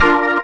B3 BMAJ 1.wav